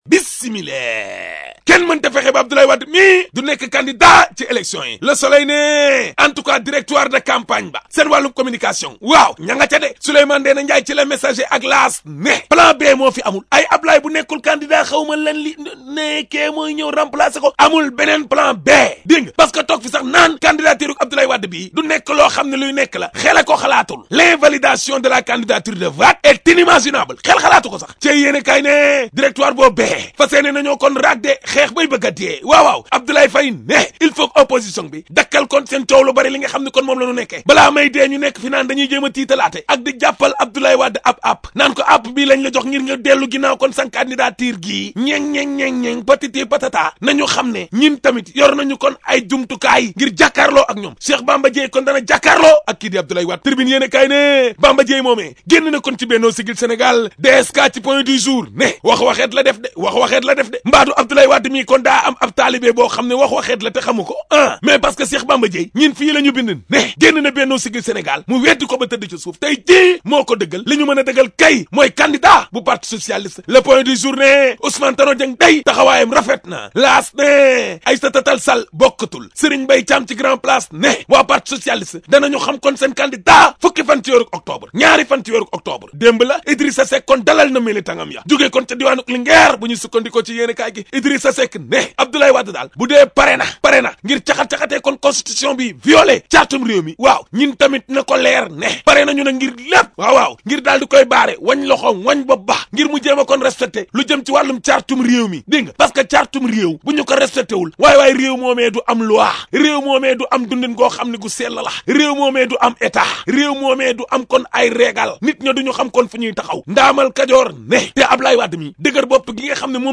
[ AUDIO ] La revue de presse d'Ahmed Aidara du 03 Octobre ( ZIK FM )